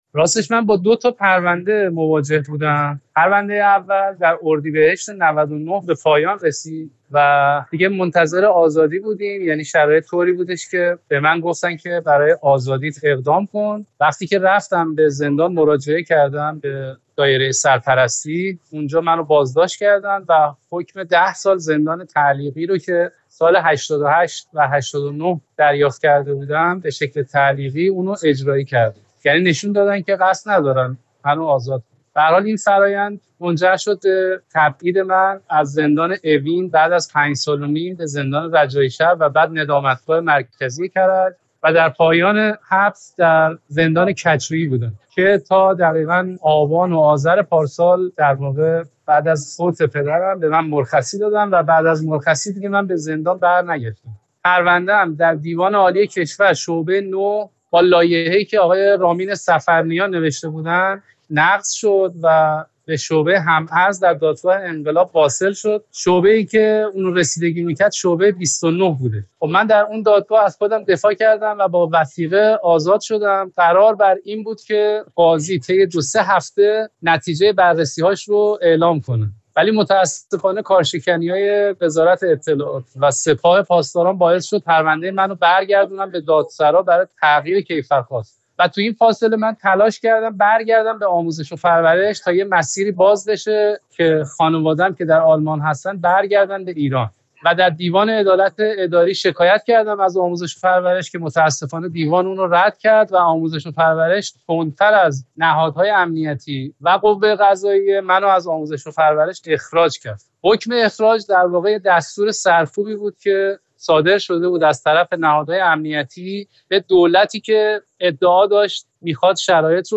گفت‌وگو
گفت‌وگویی تلفنی